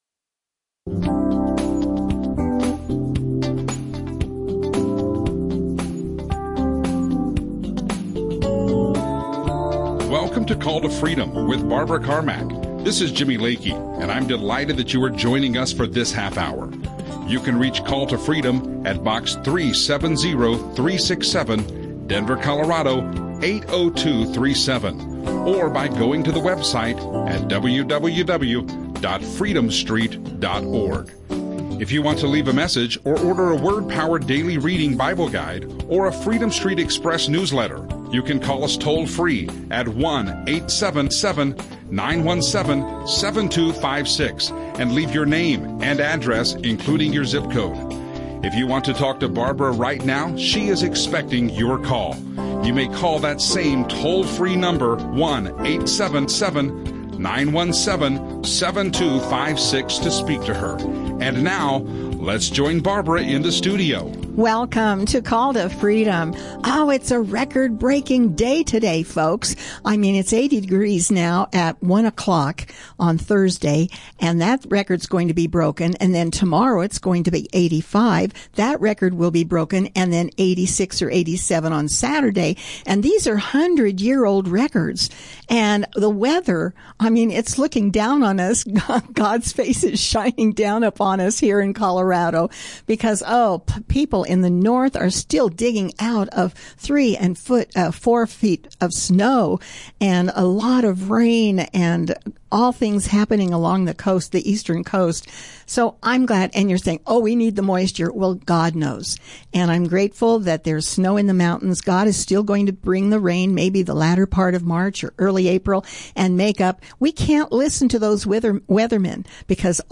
Audio teachings
Radio shows